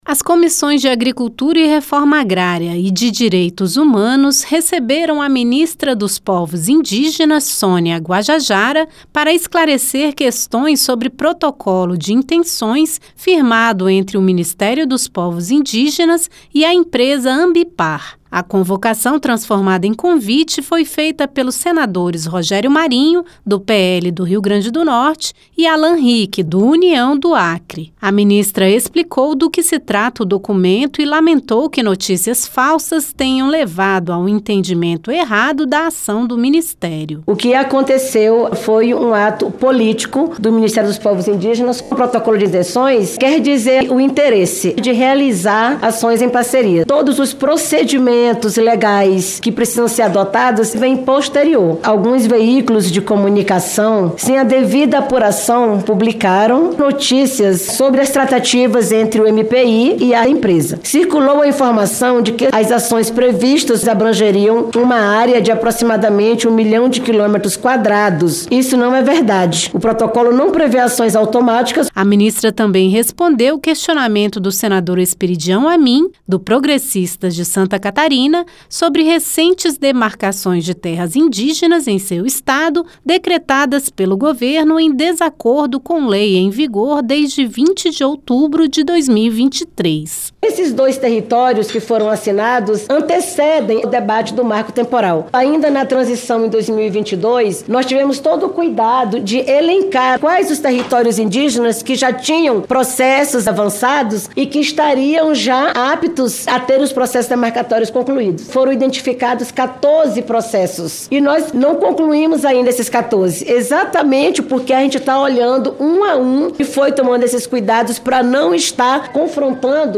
A ministra dos Povos Indígenas, Sônia Guajajara, compareceu à reunião conjunta das Comissões de Agricultura e Reforma Agrária (CRA) e de Direitos Humanos e Legislação Participativa (CDH) nesta quarta (26) para explicar protocolo de intenções firmado entre o ministério e a empresa Ambipar. Sônia Guajajara também falou sobre processos de demarcação de terras indígenas que levaram governo federal a publicar decretos.